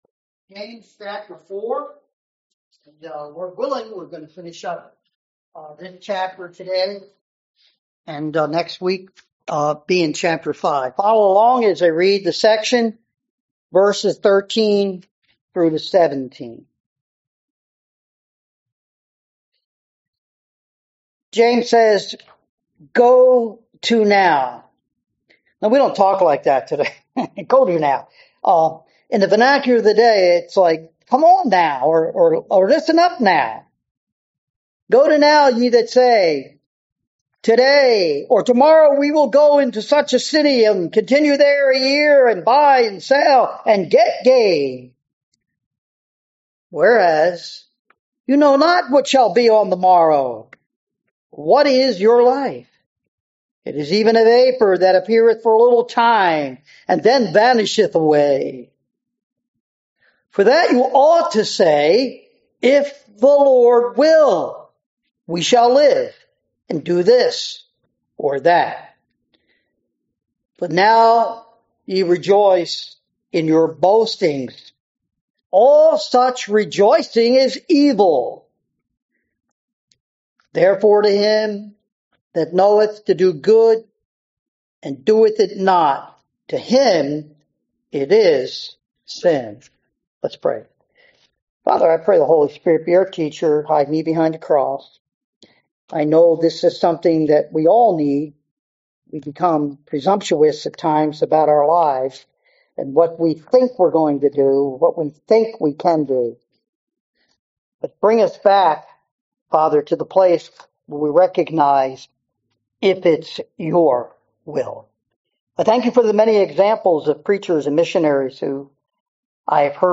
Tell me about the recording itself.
Passage: James 4:13-17 Service Type: Sunday Morning